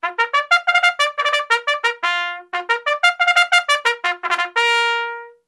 Звуки горна
Торжественный сигнал